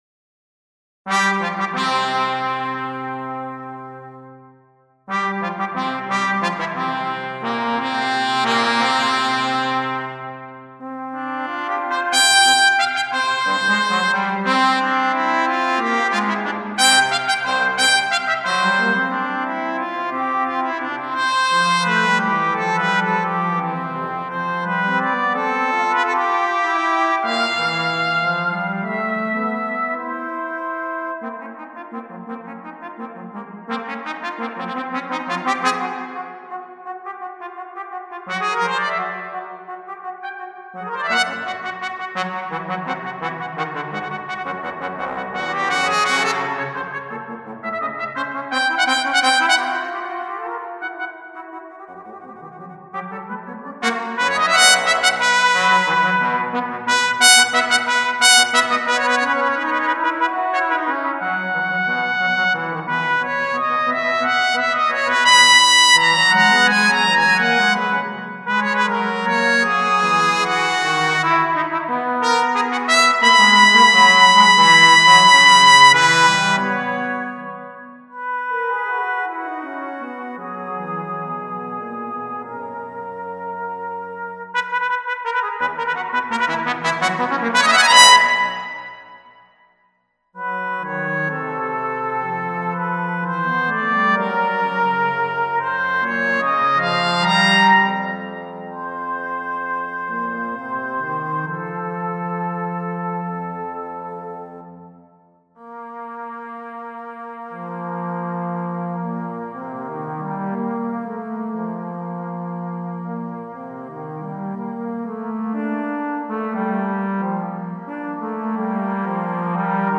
Original piece for duo trumpet and bass trombone
Duo solo trumpet in C and bass trombone.